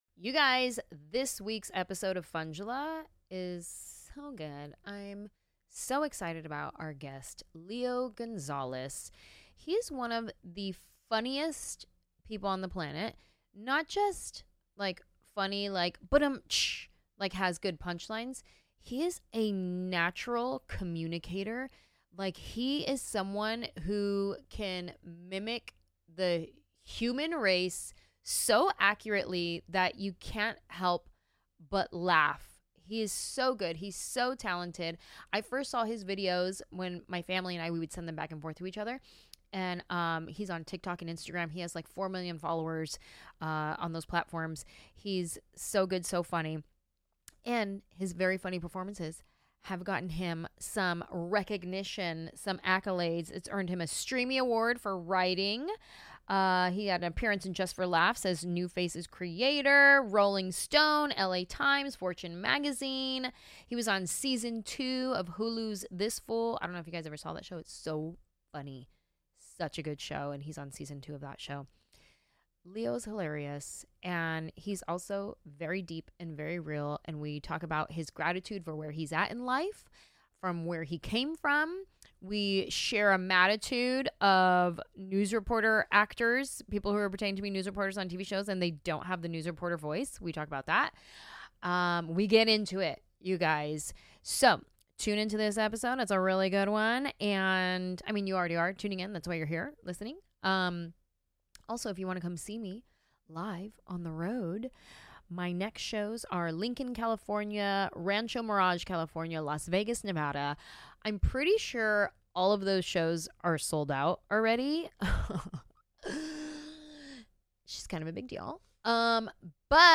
Reporting live from the Funjelah studios